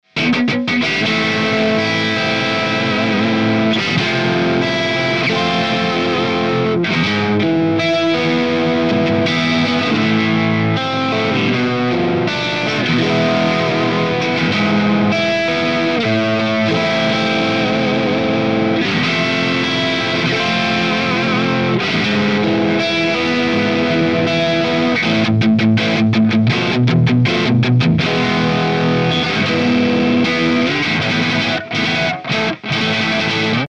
This one does it with great balance and alot of bite!
Chords
RAW AUDIO CLIPS ONLY, NO POST-PROCESSING EFFECTS
Hi-Gain